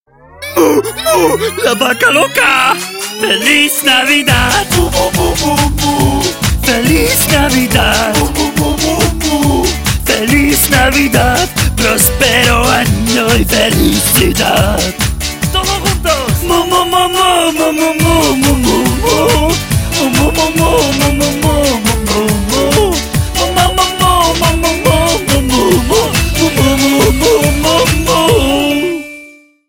Category: Ringtone